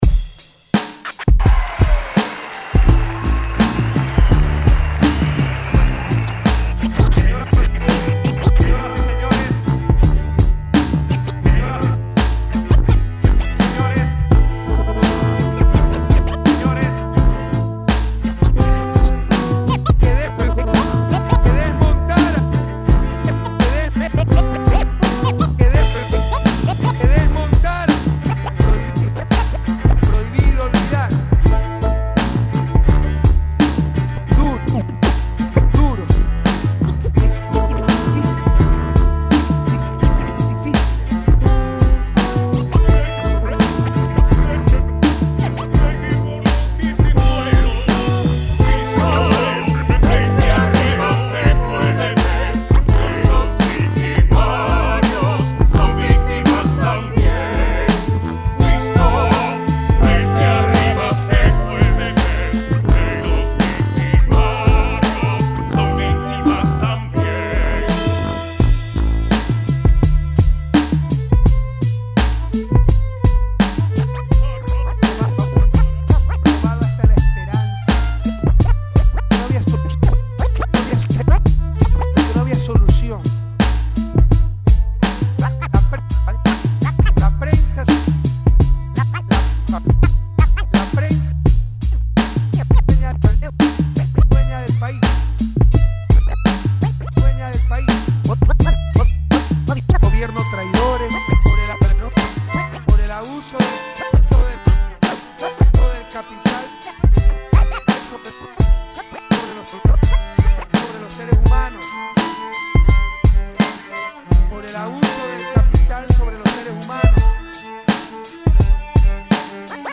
Canción
tuba y arreglo vientos.
corno.
violonchelo.
voz lírica barítono.
voz lírica soprano.
voz lírica tenor.